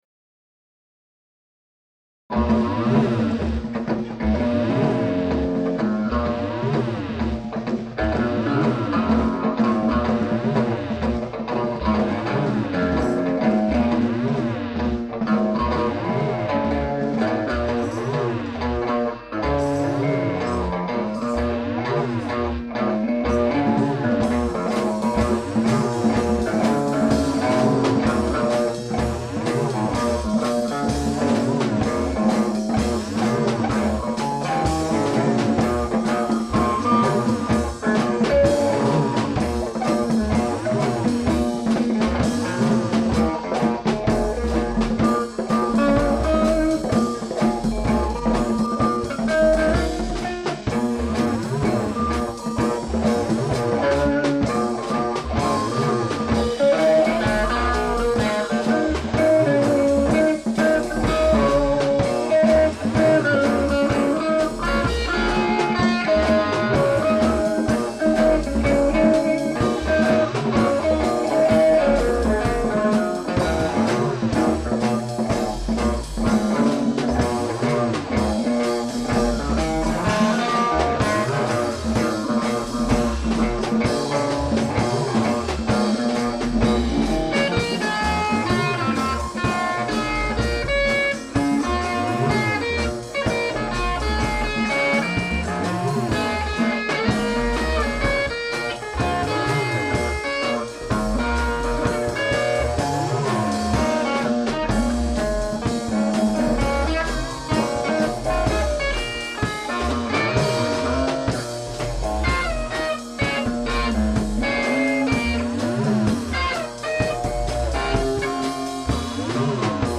e-bass
guitar
voc, perc.
perc., sax, flute
tuba, microsynth
drums, perc.